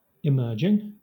Ääntäminen
Southern England
RP : IPA : /iˈmɜːd͡ʒɪŋ/